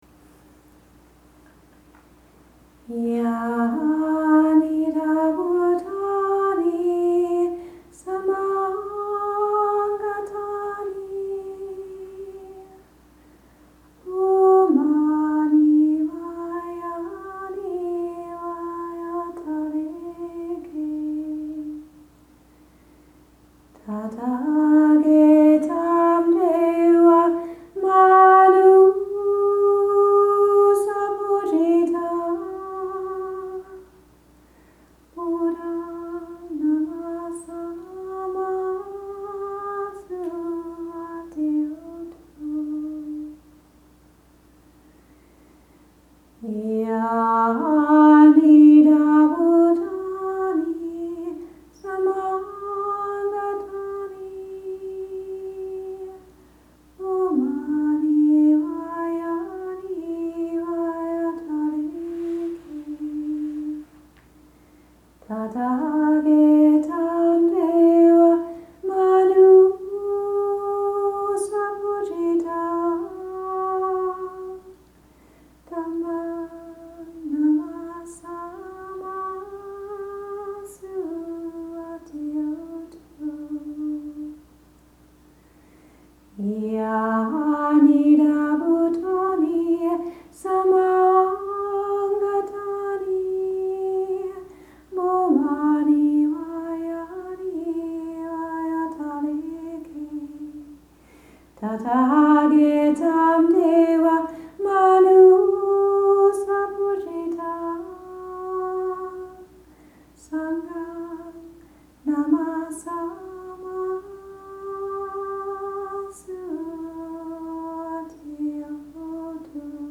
The Three Buddhist Refuges          Chanting